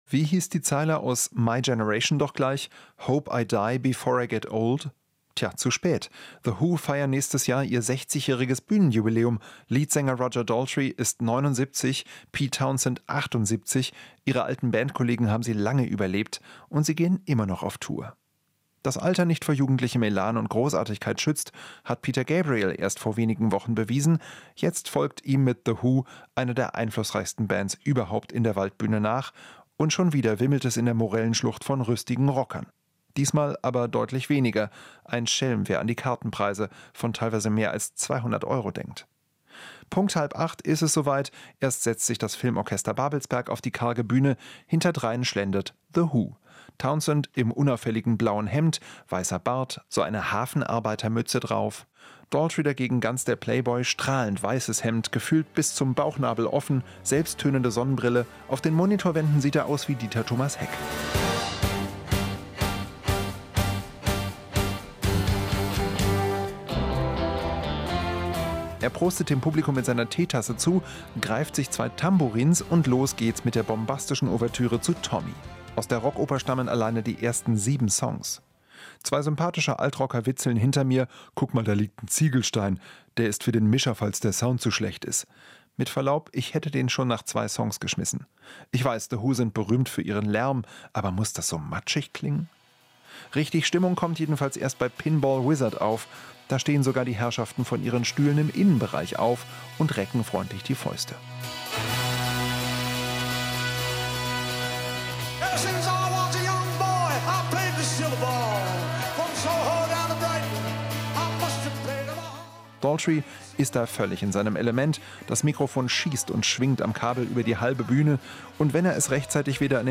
Inforadio Nachrichten, 06.08.2023, 01:00 Uhr - 06.08.2023